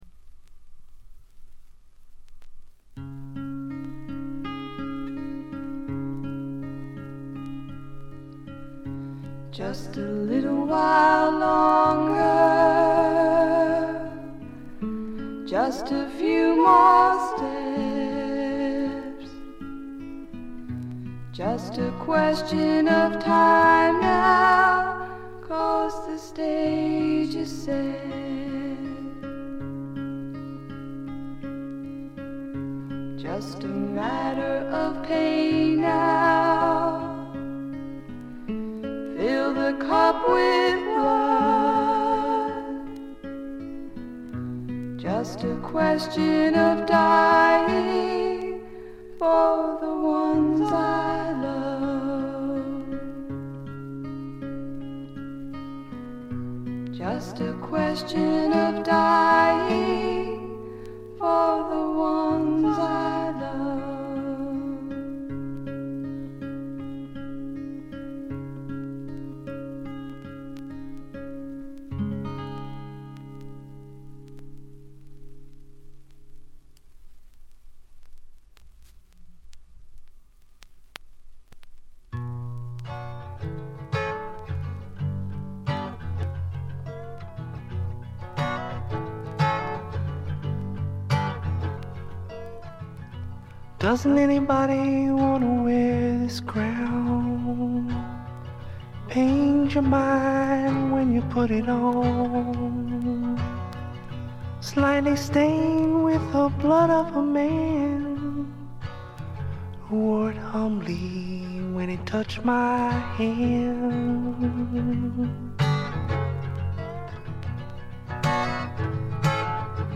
バックグラウンドノイズ、チリプチやや多めですが鑑賞を妨げるようなものはありません。
試聴曲は現品からの取り込み音源です。